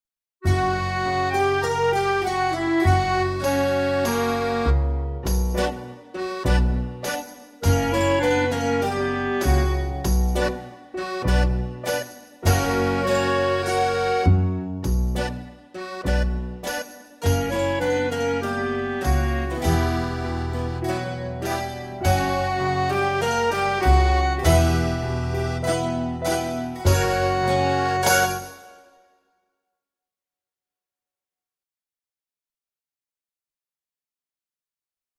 VS Greek Night Out (backing track)